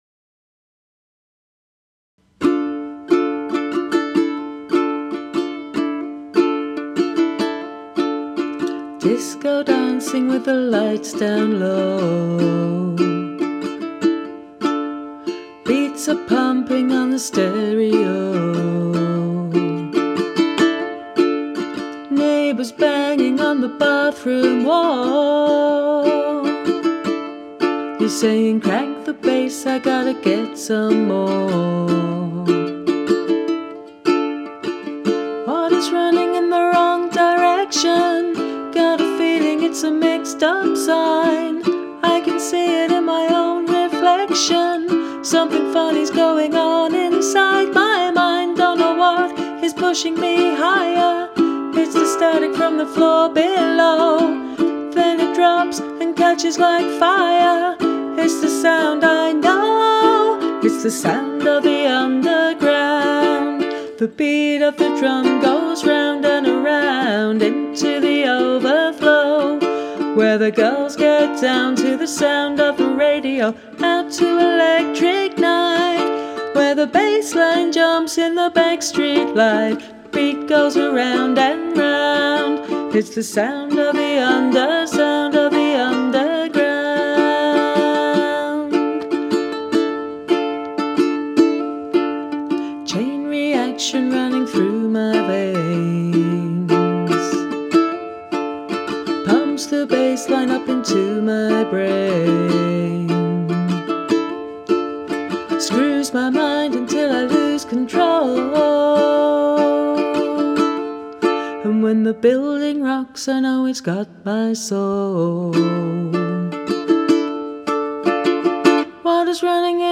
girl group